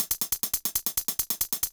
drums02.wav